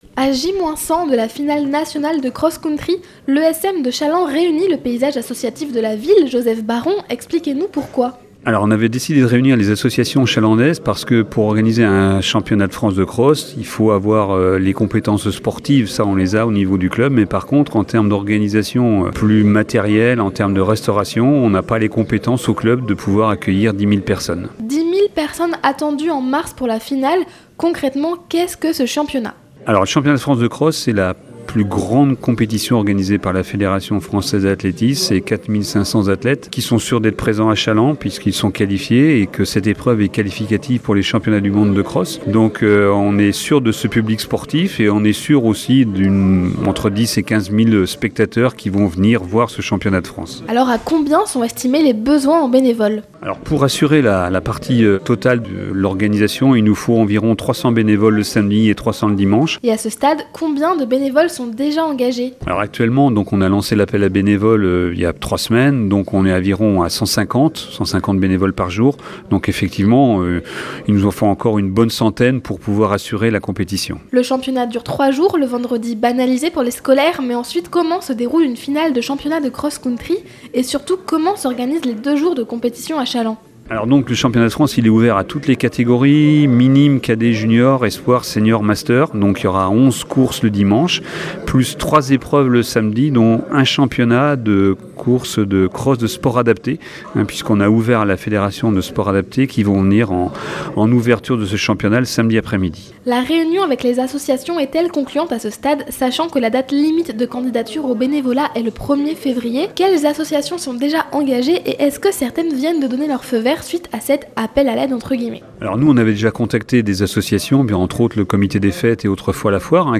Les Reportages de NOV FM